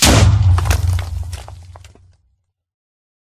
explode3